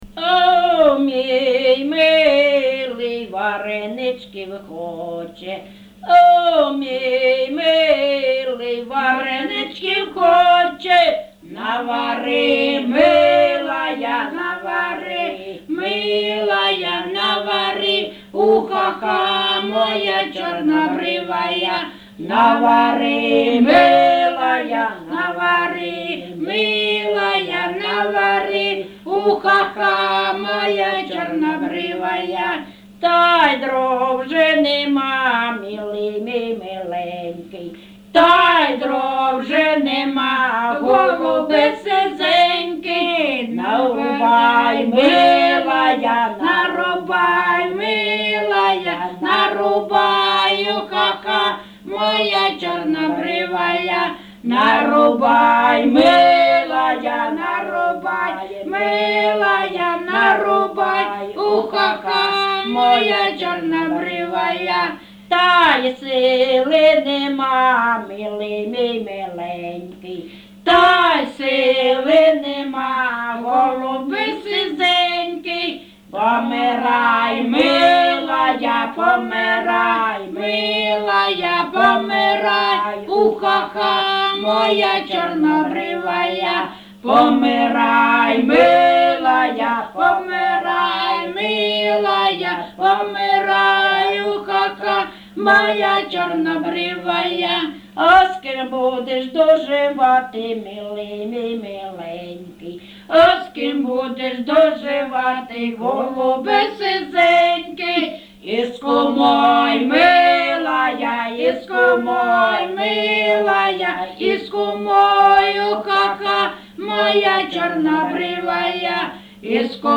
ЖанрЖартівливі
Місце записум. Старобільськ, Старобільський район, Луганська обл., Україна, Слобожанщина